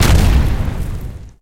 sounds_explosion.ogg